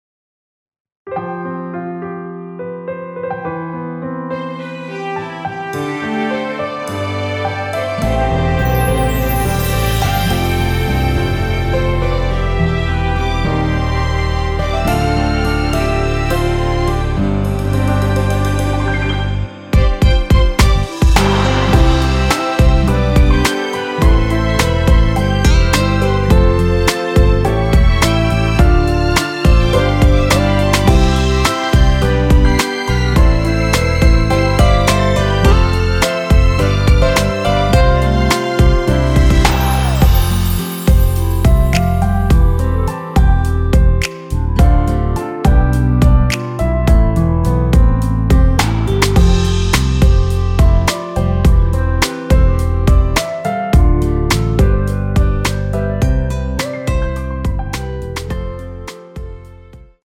대부분의 남성분이 부르실수 있는 키로 제작하였습니다.(미리듣기 확인)
원키에서(-8)내린 멜로디 포함된 MR입니다.
앞부분30초, 뒷부분30초씩 편집해서 올려 드리고 있습니다.